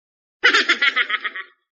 eat-bulaga-sound-effect-laugh-track_ejHtIxz.mp3